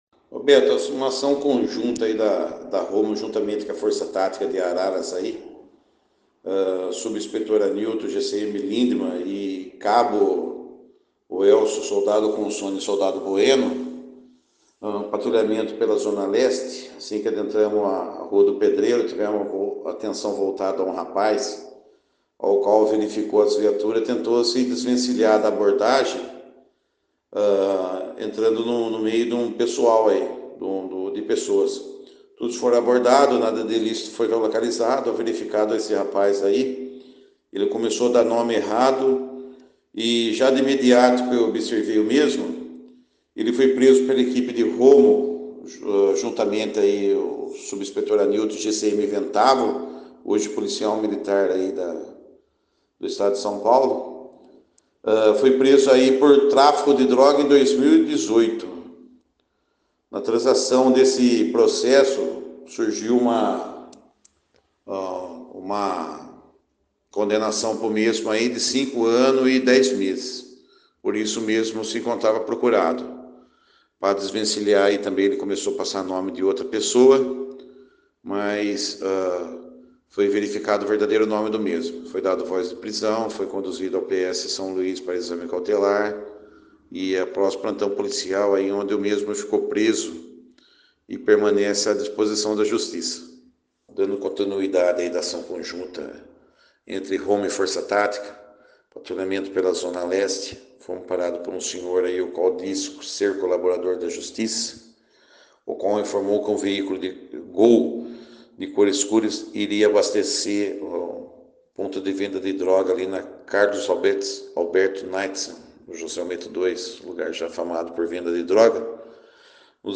As duas ocorrências foram apresentadas no pantão da Central de Polícia Judiciária.